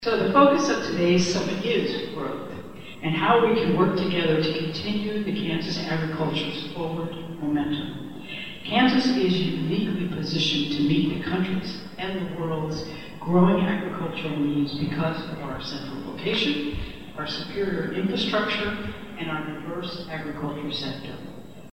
Governor Kelly addresses the 2022 Kansas Ag Summit in Manhattan